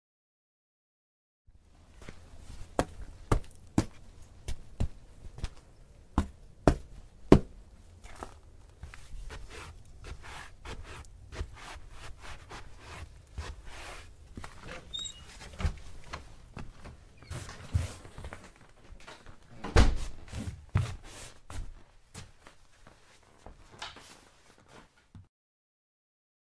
内部乘客平面（现代）
描述：室内客机/喷气机现代，一般的氛围/气氛
Tag: 飞机 飞机 室内 飞机 现代 客运 平面